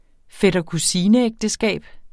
Udtale [ fεdʌkuˈsiːnə- ]